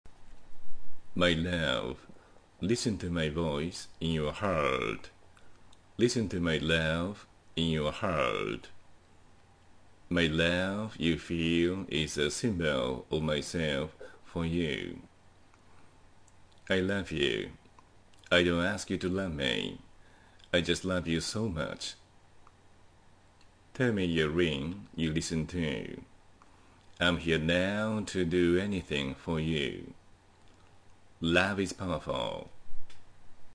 本日は、”Love is powerful”というタイトルの英語の詩を読者の皆さんにプレゼントします。
⇒ Love is powerful（詩・英語音声） <<「 マルカントワーヌ・シャルパンティエ